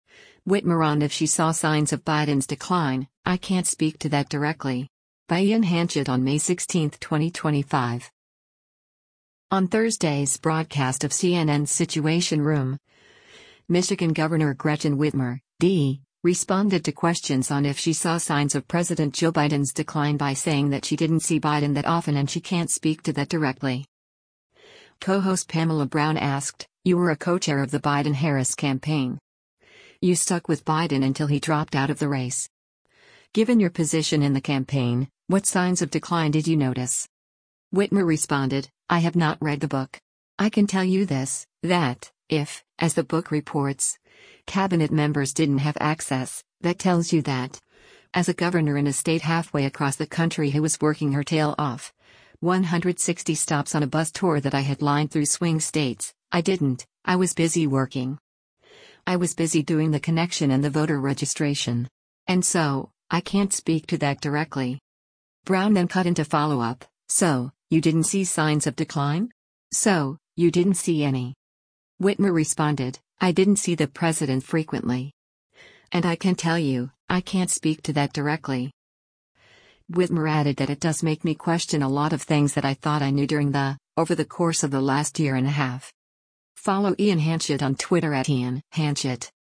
On Thursday’s broadcast of CNN’s “Situation Room,” Michigan Gov. Gretchen Whitmer (D) responded to questions on if she saw signs of President Joe Biden’s decline by saying that she didn’t see Biden that often and she “can’t speak to that directly.”